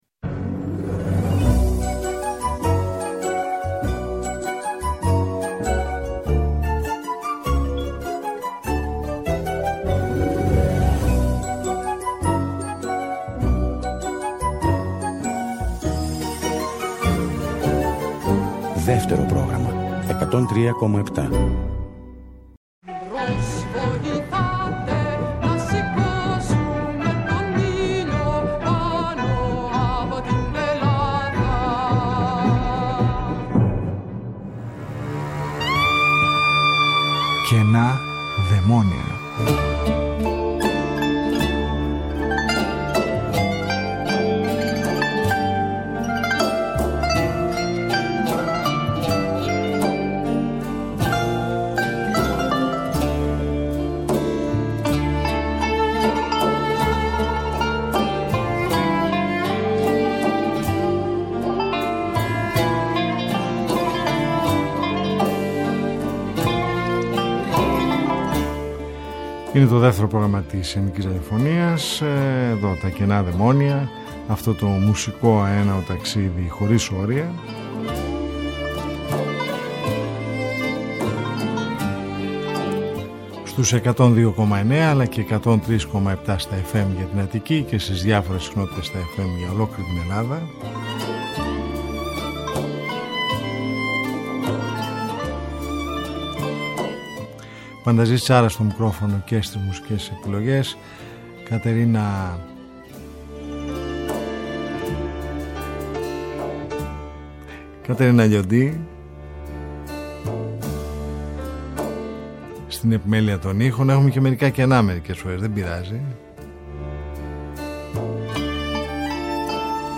Μια ραδιοφωνική συνάντηση κάθε Σάββατο στις 22:00 που μας οδηγεί μέσα από τους ήχους της ελληνικής δισκογραφίας του χθες και του σήμερα σε ένα αέναο μουσικό ταξίδι.